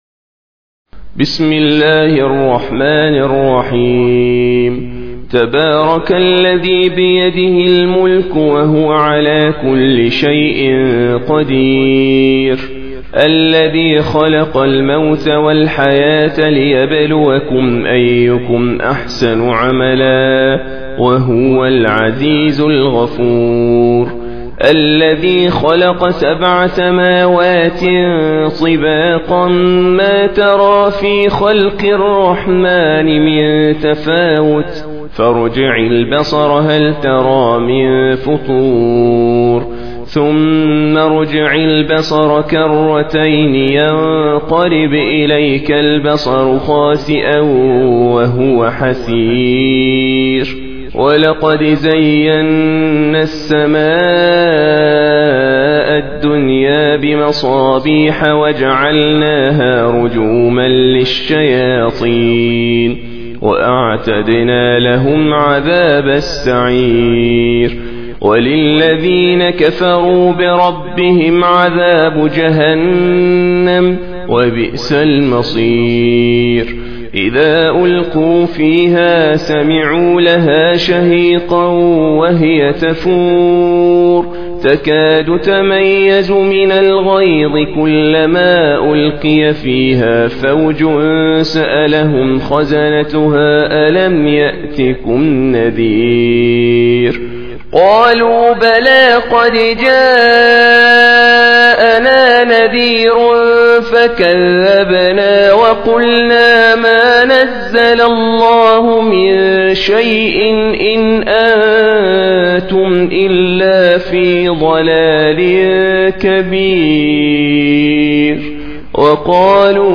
Surah Sequence تتابع السورة Download Surah حمّل السورة Reciting Murattalah Audio for 67. Surah Al-Mulk سورة الملك N.B *Surah Includes Al-Basmalah Reciters Sequents تتابع التلاوات Reciters Repeats تكرار التلاوات